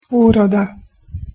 pronunciation_sk_uroda.mp3